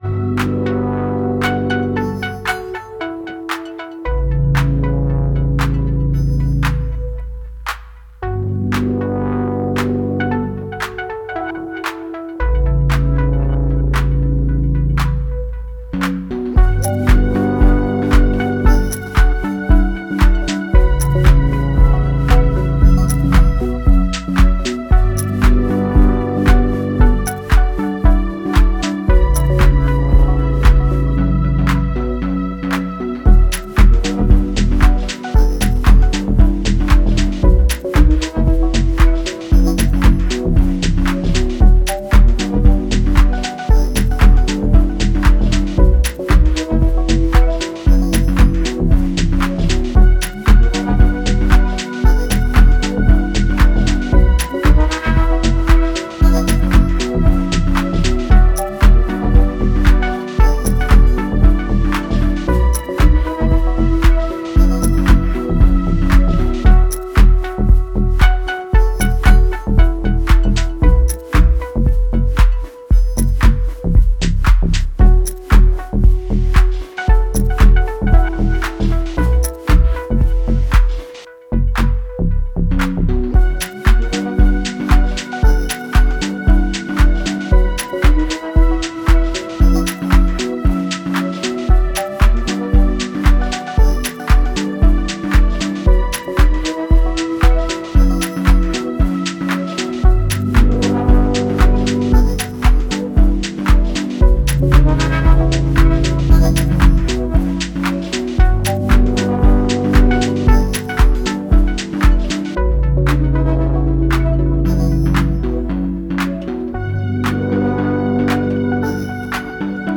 Working on a tune. Digitone, OT and a bit of 707 all recorded into Ableton.